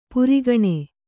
♪ purigaṇe